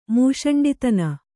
♪ mūṣaṇḍitana